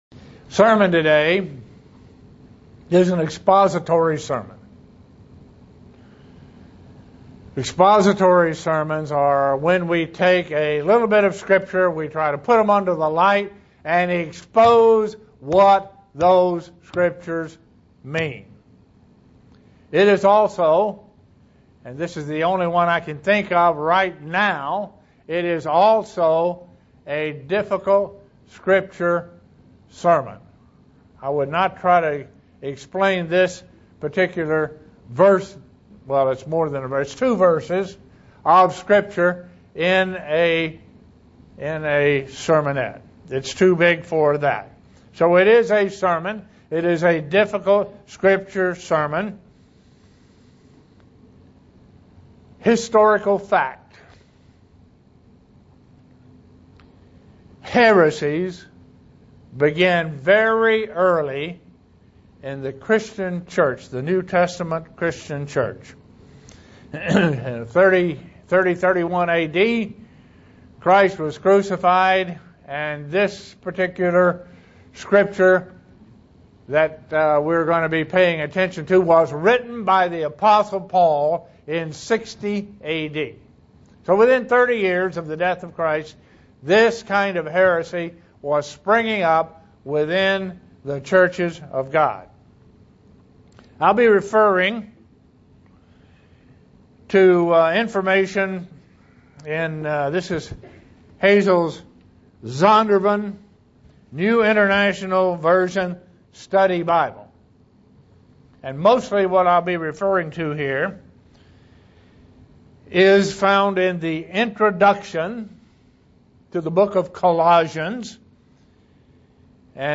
Given in Buffalo, NY
SEE VIDEO BELOW UCG Sermon Studying the bible?